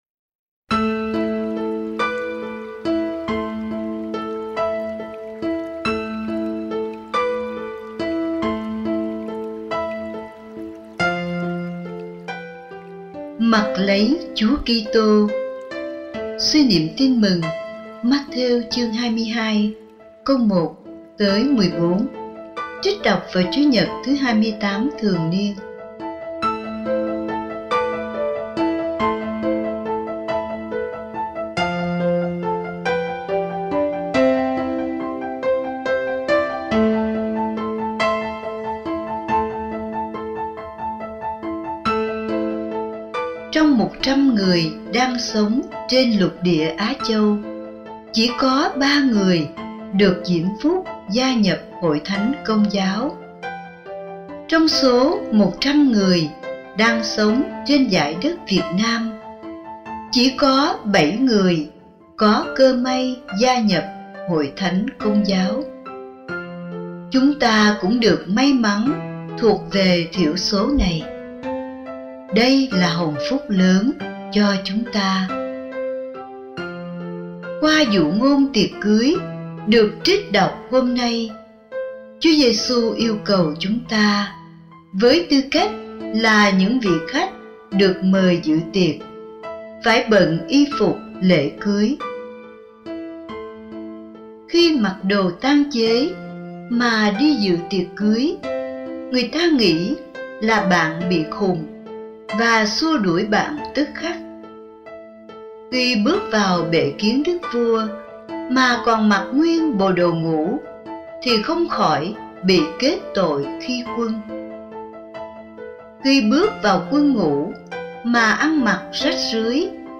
(Suy niệm Tin mừng Chúa Nhật 28 thường niên)